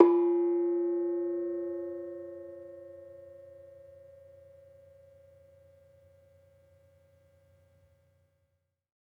Bonang-F3-f.wav